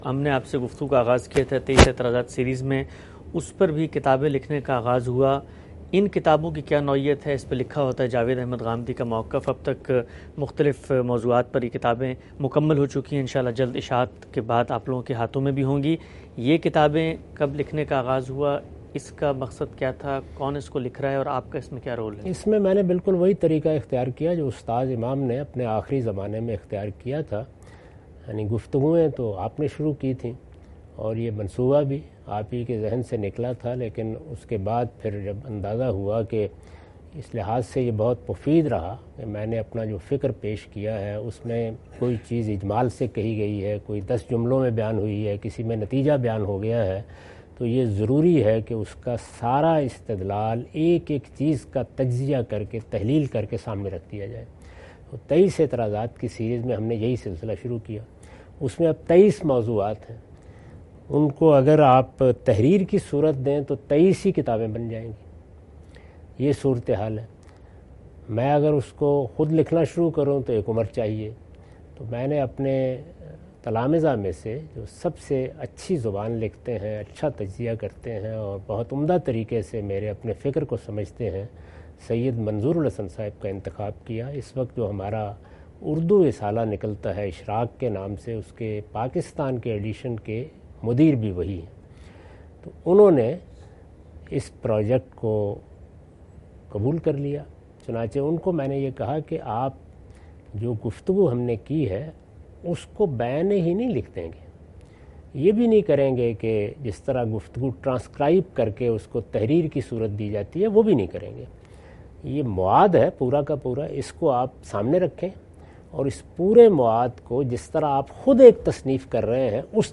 Category: Reflections / Questions_Answers /